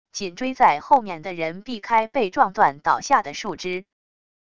紧追在后面的人避开被撞断倒下的树枝wav下载